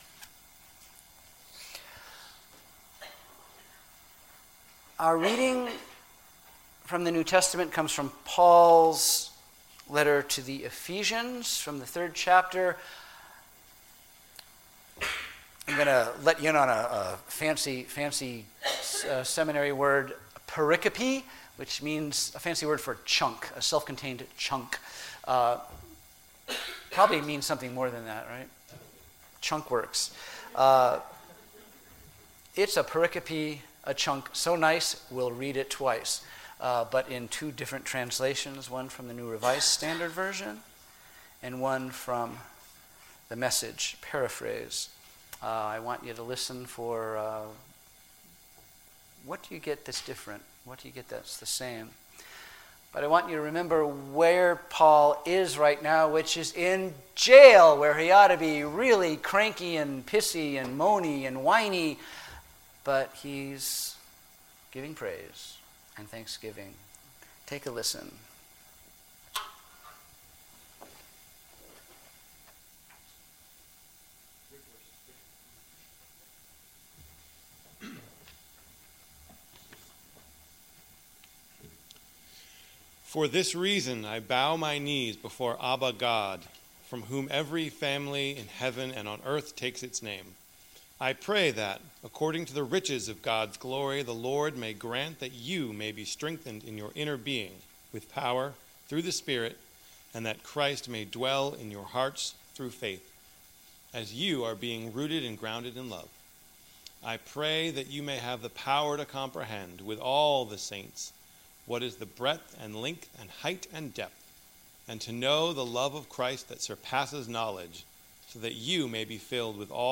Sermons What Is God Really Like?